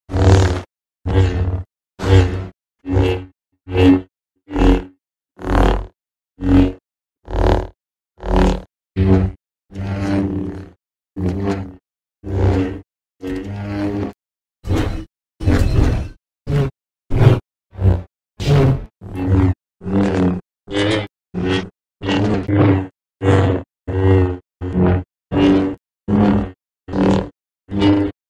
Звуки лазерного меча
Взмахи светового меча